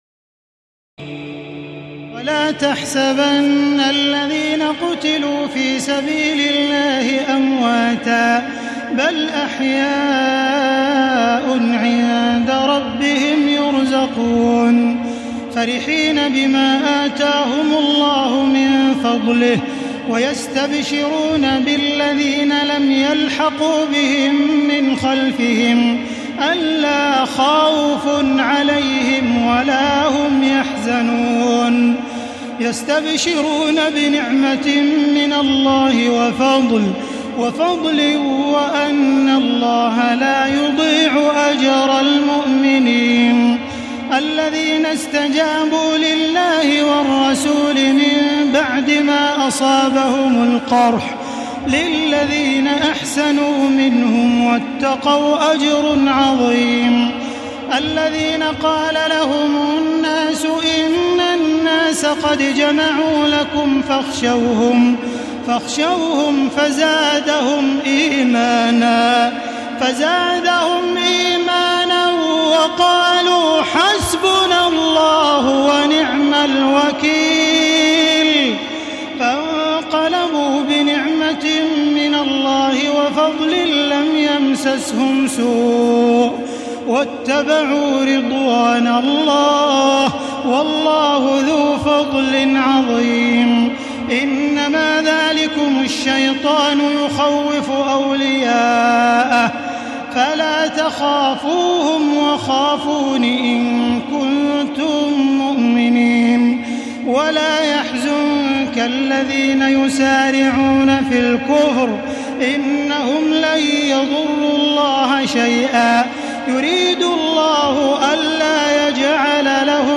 تراويح الليلة الرابعة رمضان 1435هـ من سورتي آل عمران (169-200) و النساء (1-24) Taraweeh 4 st night Ramadan 1435H from Surah Aal-i-Imraan and An-Nisaa > تراويح الحرم المكي عام 1435 🕋 > التراويح - تلاوات الحرمين